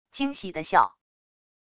惊喜的笑wav音频